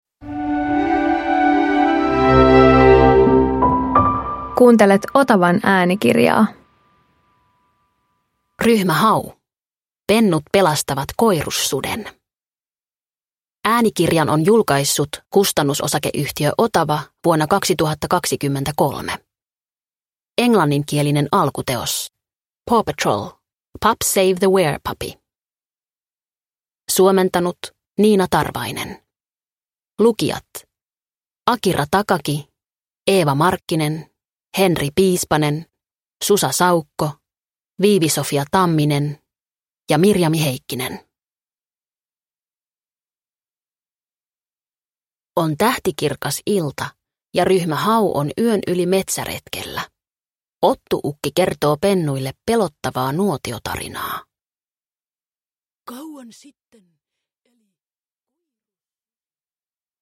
Ryhmä Hau - Pennut pelastavat koiruussuden – Ljudbok – Laddas ner